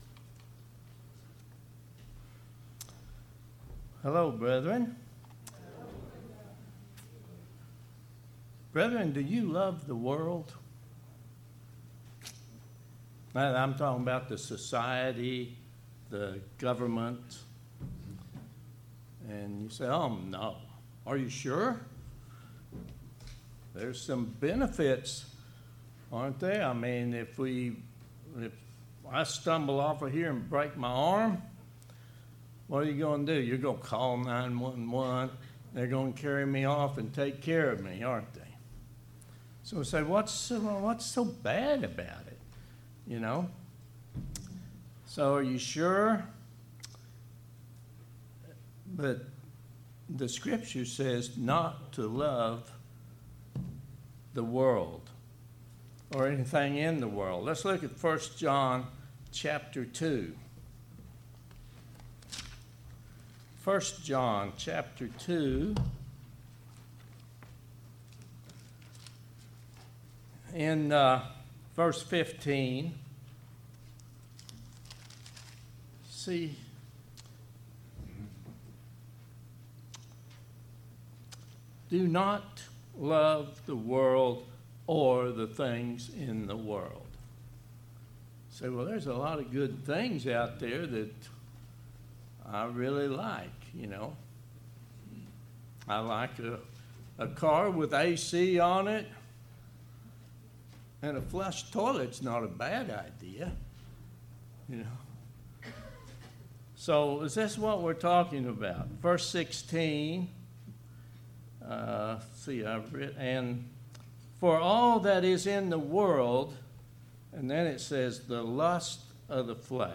Given in Oklahoma City, OK